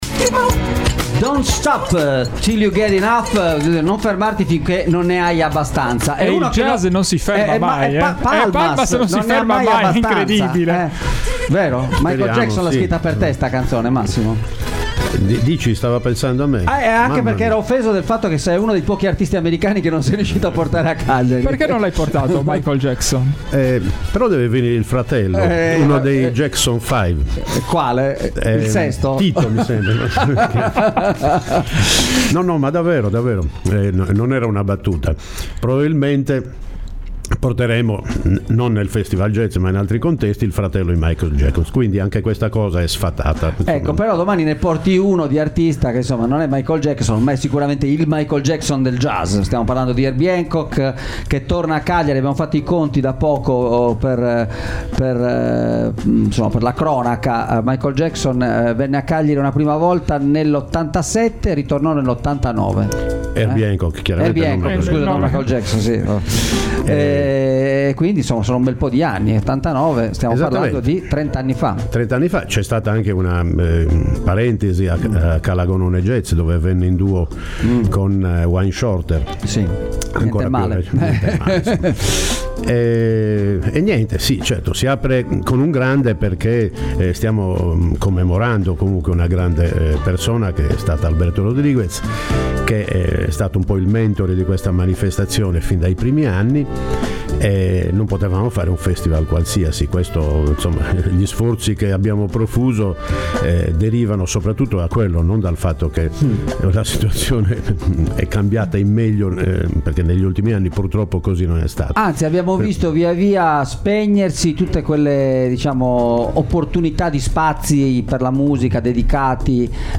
37 anni di Jazz in Sardegna - intervista